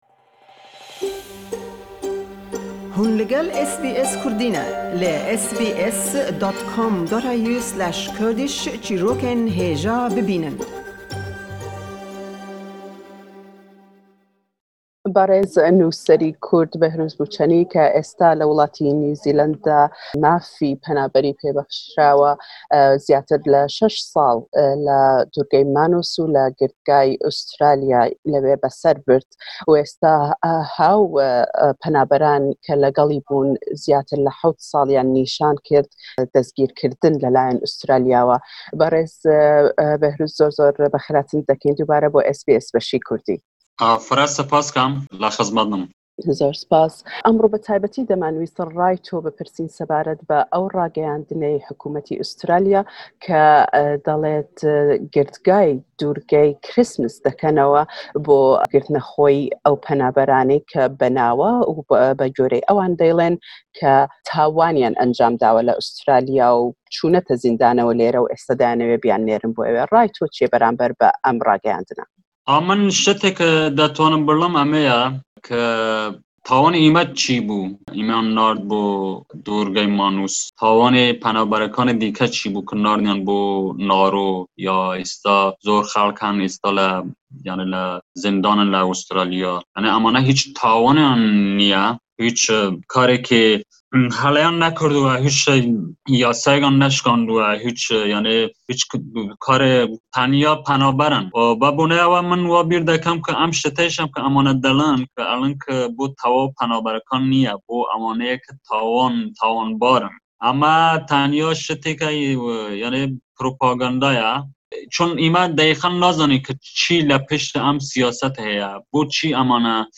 Nûserî Kurd û penaxwazî pêşû Bêhrûz Bûçanî (Behrouz Boochani) le gell SBS Kurdî dedwêt sebaret be birryarî hukûmetî Australya sebaret be kirdinewey girtgayî Durgey Krîstmas.